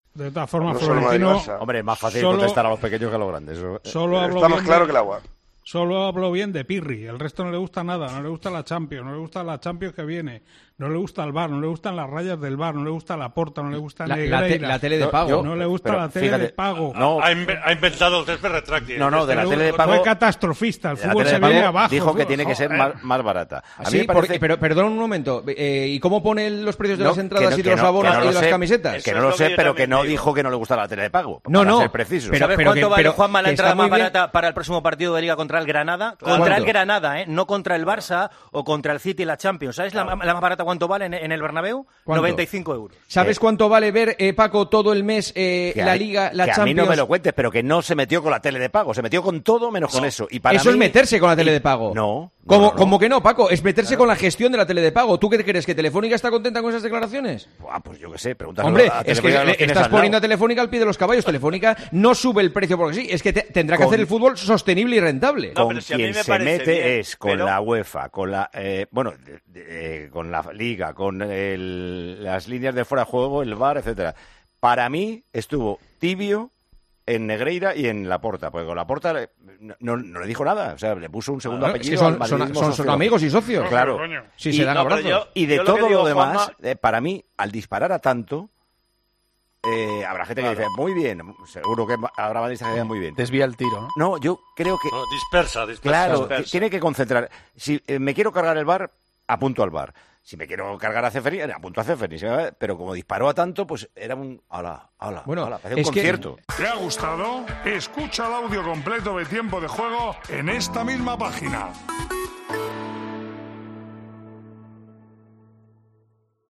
Anoche, durante el Tertulión de los domingos, el director y presentador de Tiempo de Juego, Paco González, lanzó una crítica hacia el máximo dirigente blanco por sus palabras por el caso Negreira.